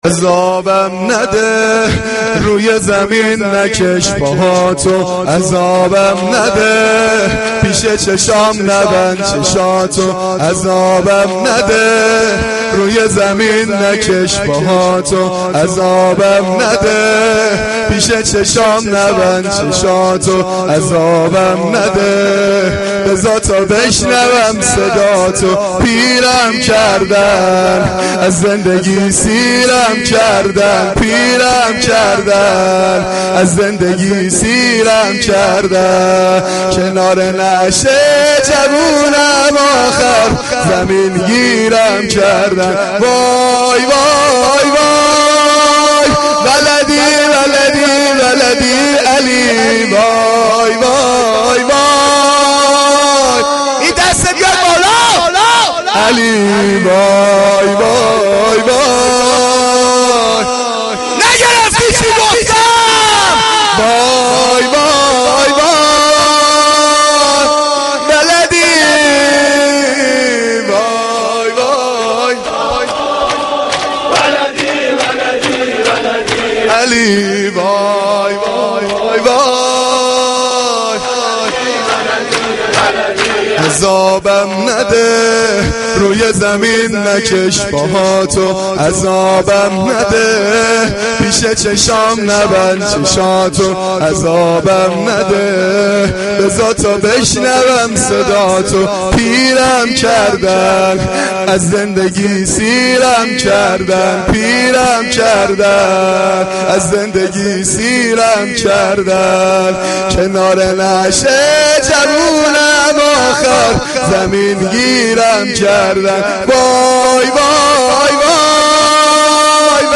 مداحی
Shab-8-Moharam-7.mp3